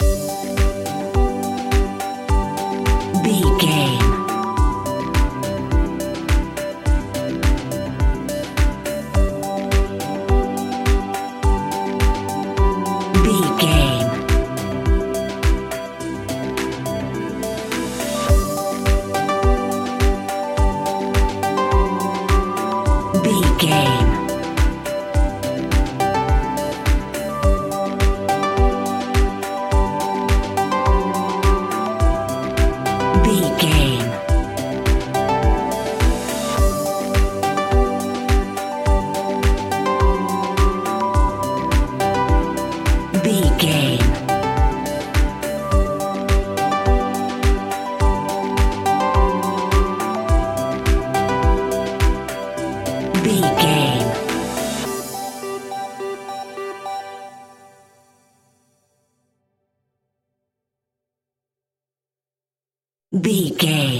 Ionian/Major
groovy
uplifting
energetic
repetitive
synthesiser
drums
electric piano
strings
electronic
techno
drum machine
synth bass